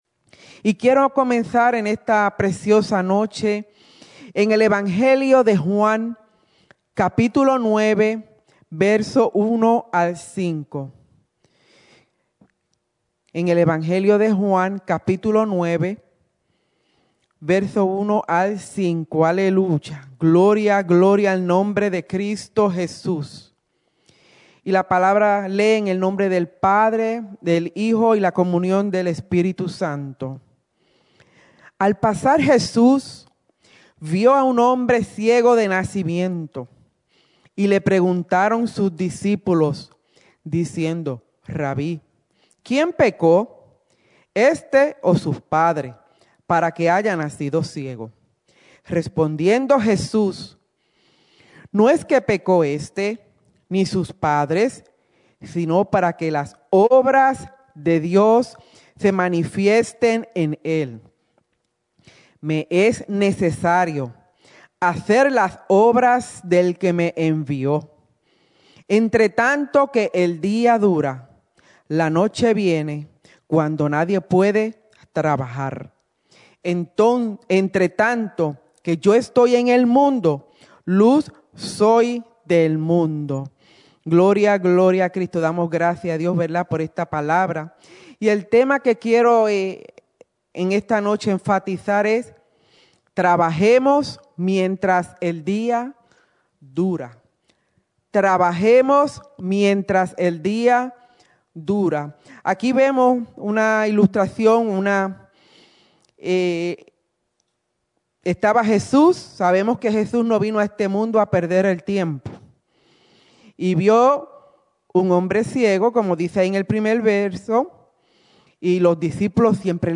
Mensaje
grabado el 6/19/20 en la Iglesia Misión Evangélica en Souderton, PA